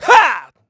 Riheet-Vox_Attack3_kr.wav